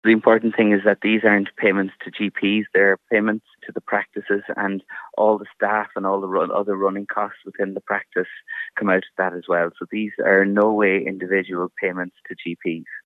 Donegal GP says medical card scheme isn’t as lucrative as it appears